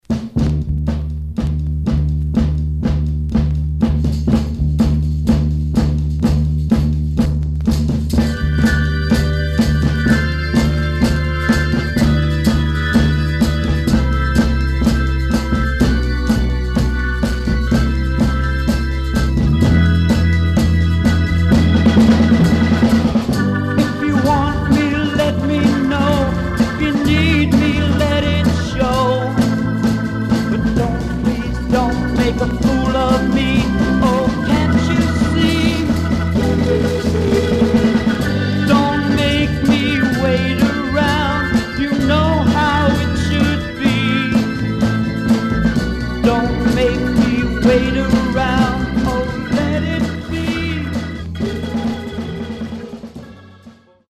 Stereo/mono Mono
Garage, 60's Punk ..........👈🏼 Condition